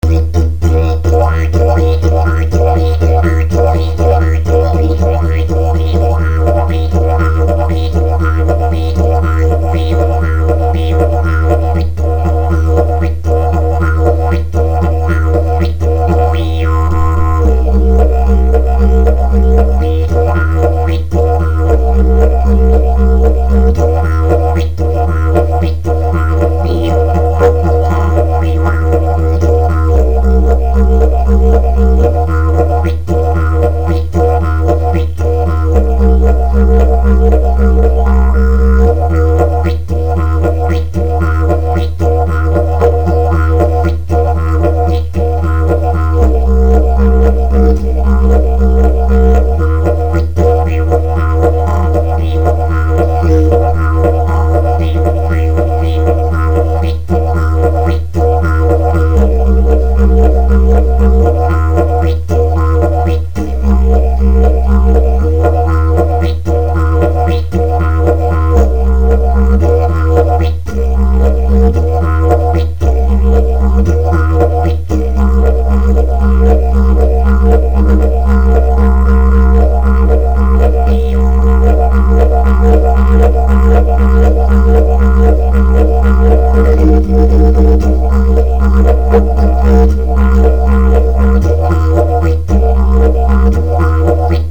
vends didg fourche didjaman